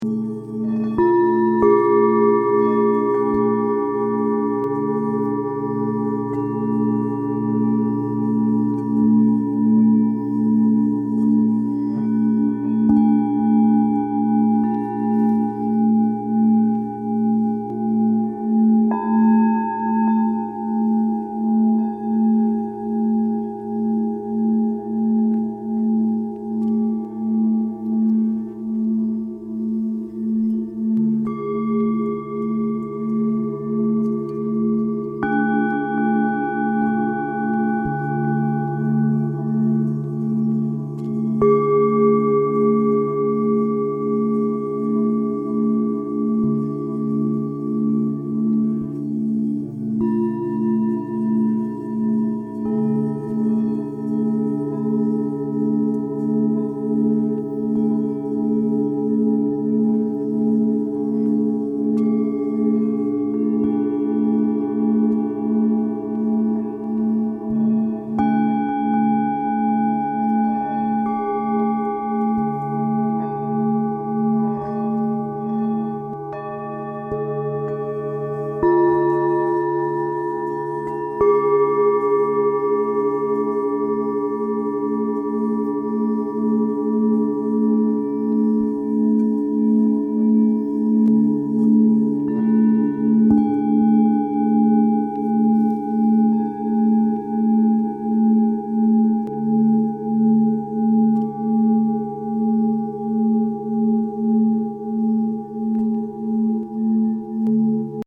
They are made of precious metals and hammered by hand to reveal their unique musical and vibrational properties. The tones, overtones and sound-waves of the bowls draw all of our attention to them and bring us into a state of ease – positively affecting the mind, central nervous system, physical body and chakras or energy centers of the body.
background-music-no-voice-1_131.mp3